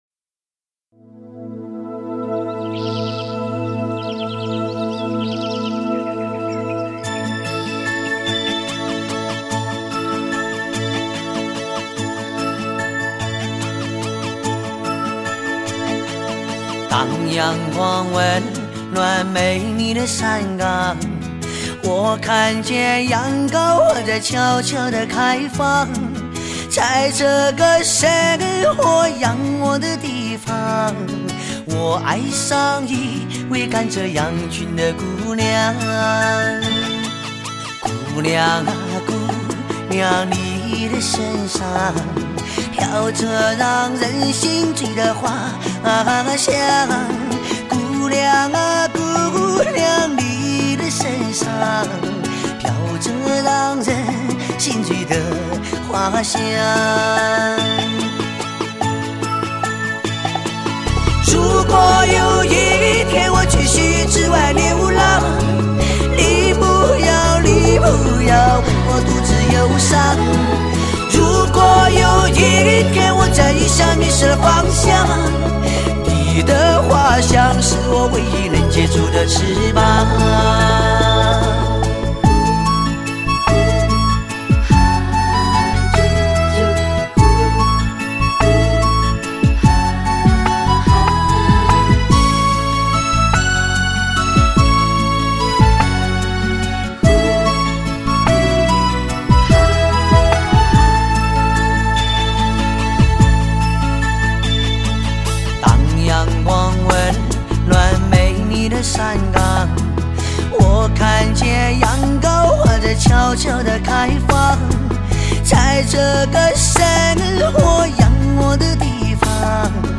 挑选最优秀的民族歌曲联手打造一部来自西藏高原的声音传奇。